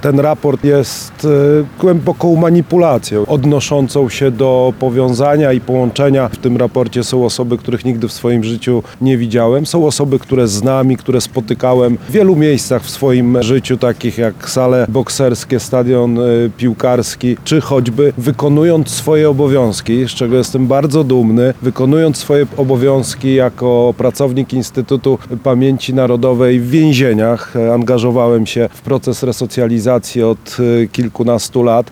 – Kandydując na prezydenta, zdawałem sobie sprawę, że ta walka będzie bardzo brutalna, ale byłoby dobrze, gdyby ta brutalność była oparta na faktach – mówił Karol Nawrocki podczas wizyty w Chełmie.